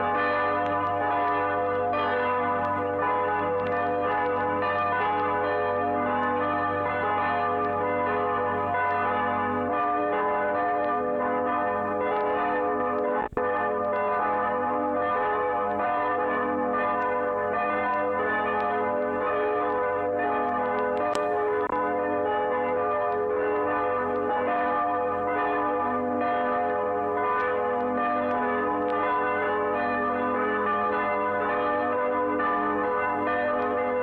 Glockengeläut der Kirche Lichtental
glockengelaeut.mp3